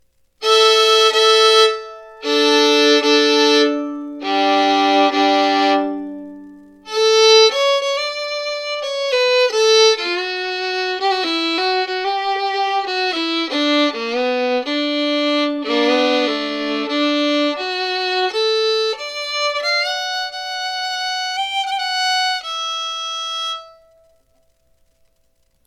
New Handmade Violin / Fiddle Outfit with case & bow - $475.00
I would classify this one as loud in volume, with an all around moderate tone quality. Some warmth and nice sounding E string!